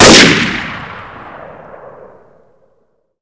scout_fire-1.wav